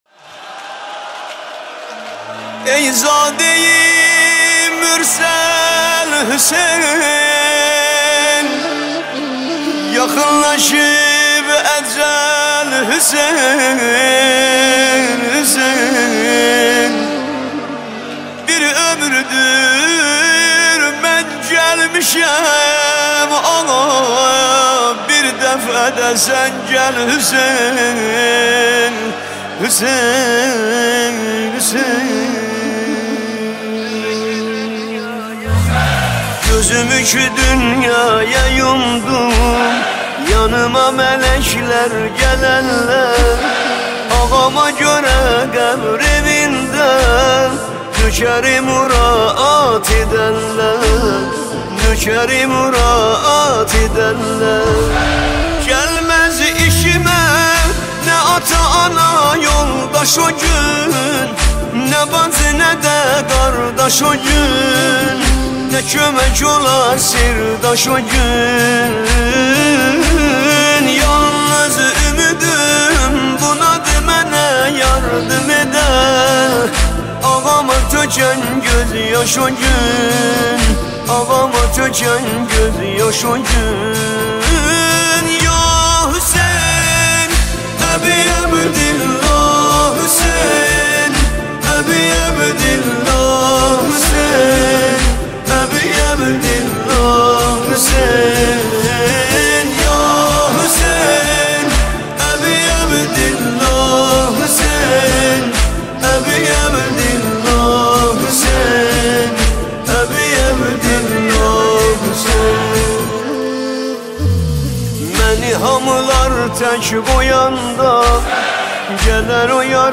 نماهنگ ترکی
نماهنگ دلنشین ترکی